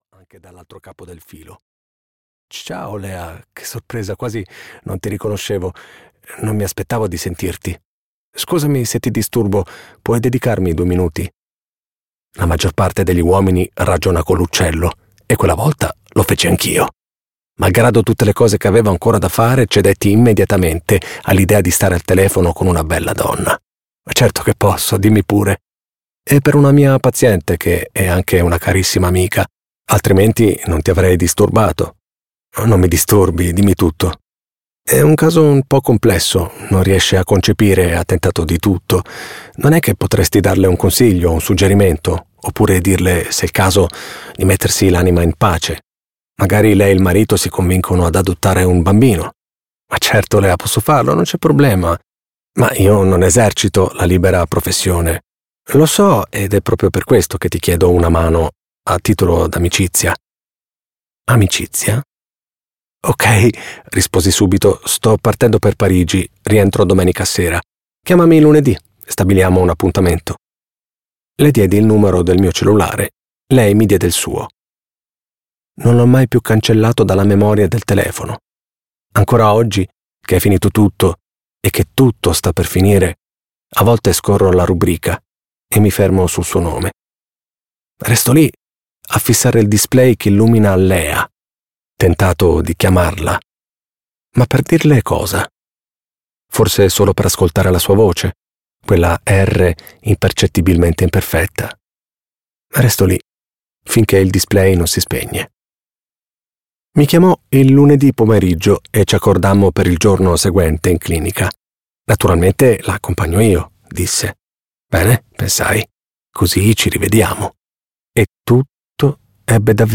"Lettera a Léontine" di Raffaello Mastrolonardo - Audiolibro digitale - AUDIOLIBRI LIQUIDI - Il Libraio